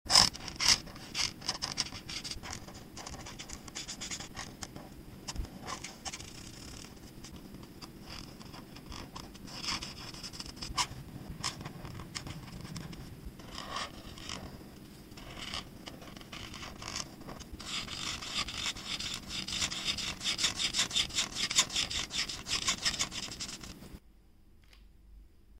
ASMR Colors: Coloring a Carrot sound effects free download
Watch every smooth glide of color and enjoy the relaxing sounds that make ASMR art so addictive.